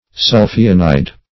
Search Result for " sulphionide" : The Collaborative International Dictionary of English v.0.48: Sulphionide \Sulph*i"on*ide\, n. (Chem.)